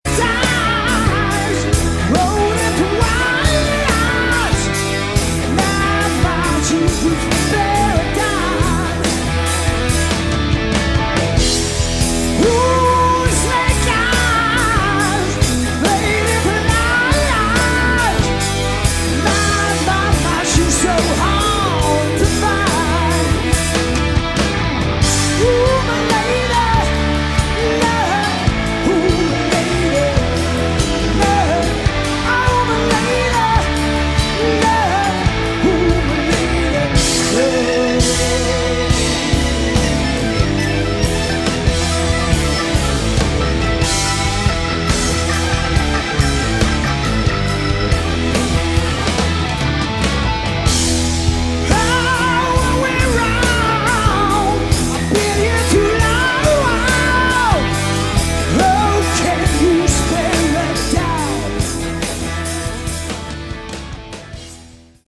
Category: Melodic Rock / AOR
guitar
keyboards, vocals
bass
drums, vocals